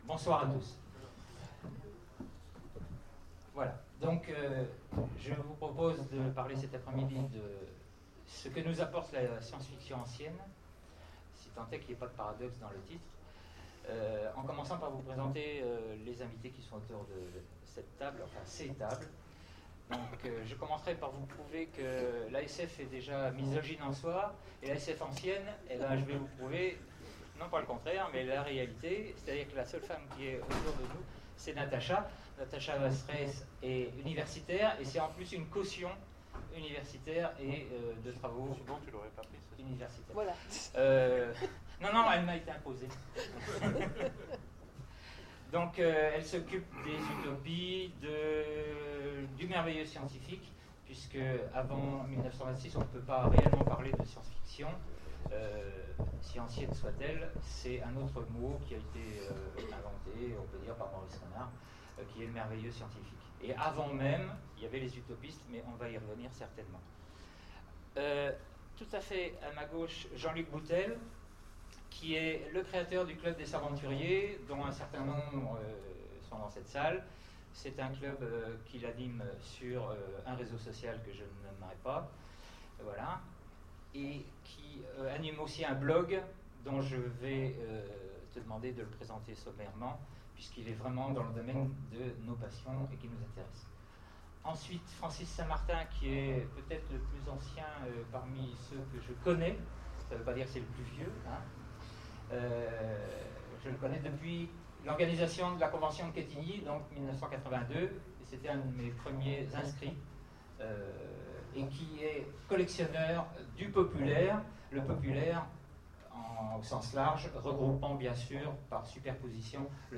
Sèvres 2013 : Conférence Que nous apporte la SF ancienne ?